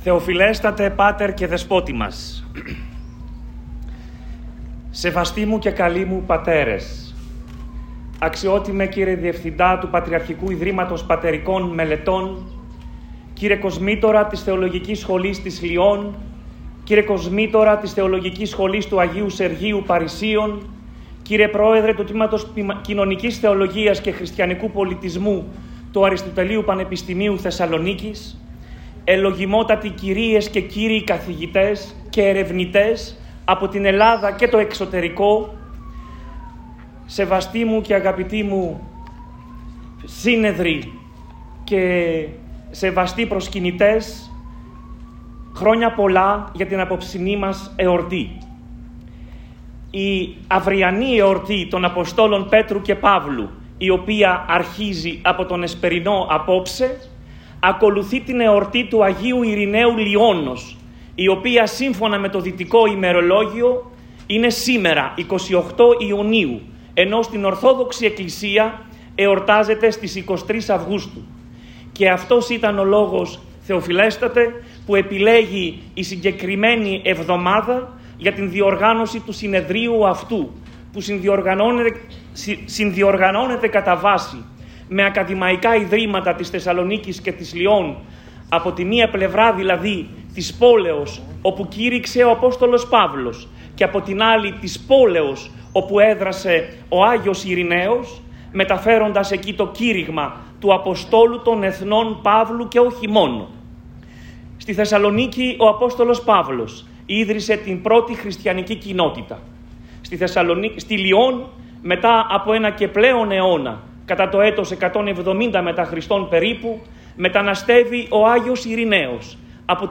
Μέγας Πανηγυρικός Εσπερινός του Αποστόλου Παύλου στην Ιερά Πατριαρχική και Σταυροπηγιακή Μονή Βλατάδων - Ορθοδοξία News Agency